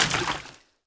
etfx_explosion_liquid2.wav